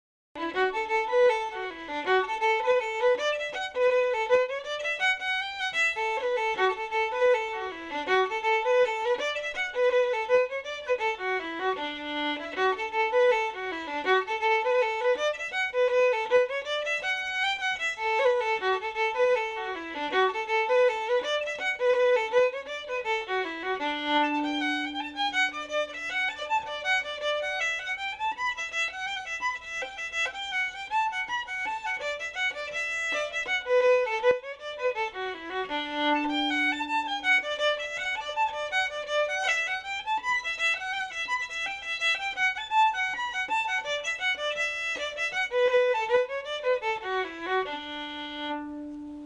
Session Tunes